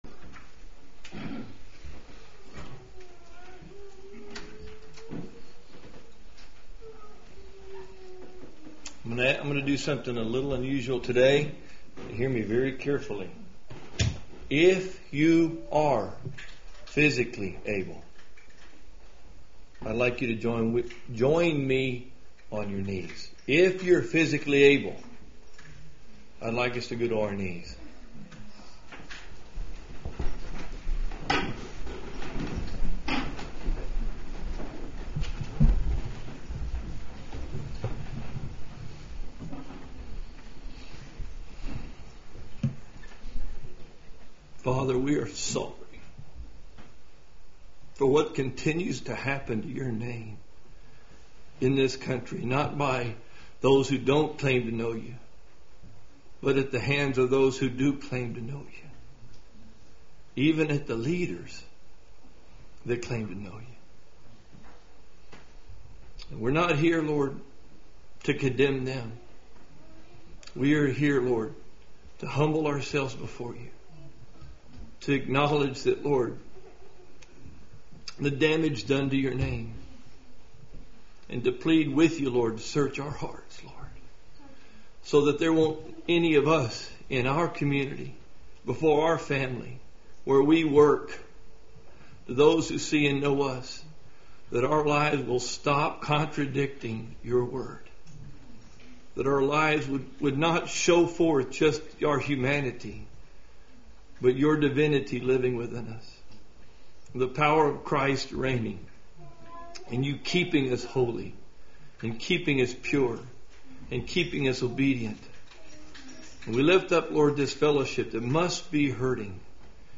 In this sermon, the speaker emphasizes the importance of testing leaders and holding them accountable to be examples to the flock. The speaker raises the question of where compromise begins and warns against straying from the truth of Scripture. The sermon highlights the freedom to choose that believers have been given by Christ and encourages walking in the Spirit to avoid fulfilling the lusts of the flesh.